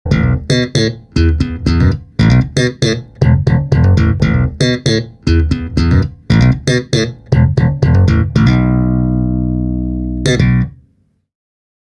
Boosting the bottom end, as well as adding bite, results in a classic slap tone:
Slap